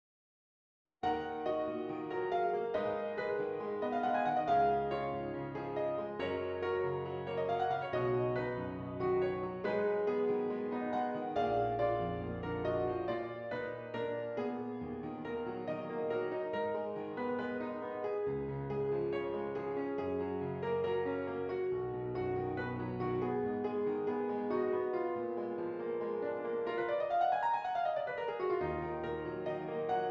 Does Not Contain Lyrics
E Flat Minor
Allegro espressivo